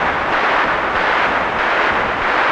RI_RhythNoise_95-04.wav